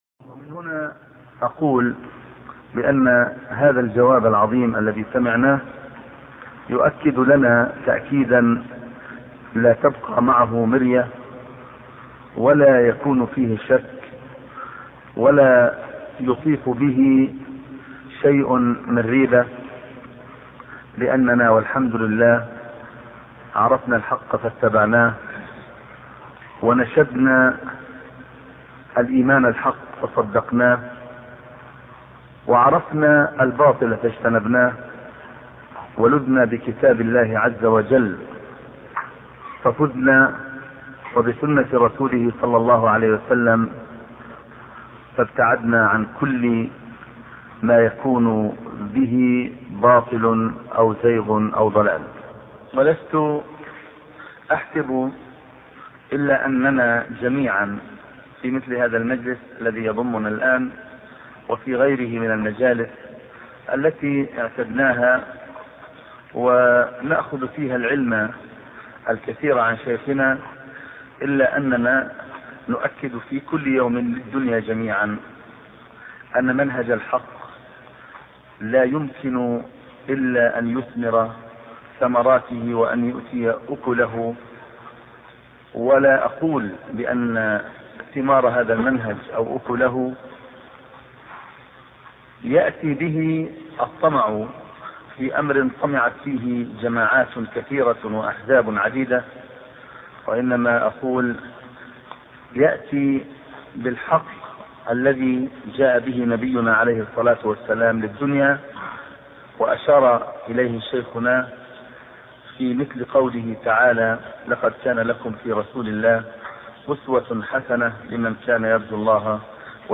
شبكة المعرفة الإسلامية | الدروس | التحذير من فتنة التكفير 2 |محمد ناصر الدين الالباني